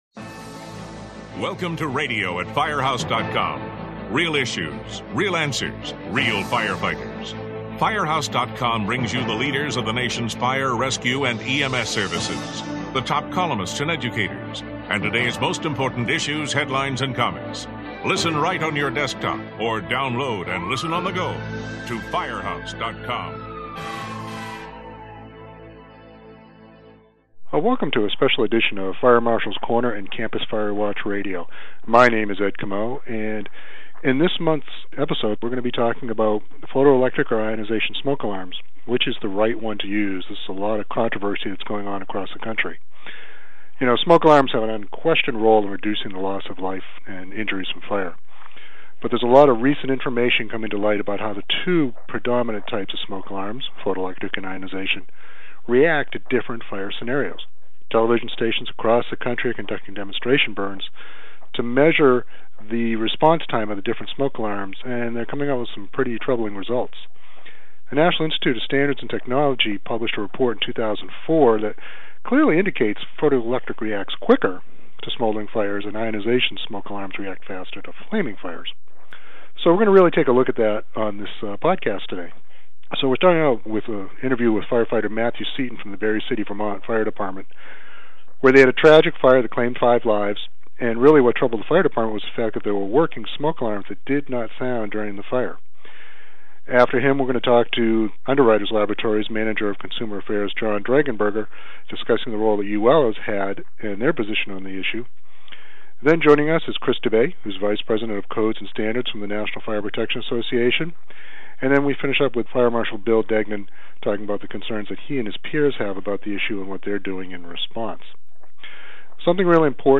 Finishing up is New Hampshire Fire Marshal Bill Degnan talking about the concerns that he and his peers have about this issue and what they are doing in response.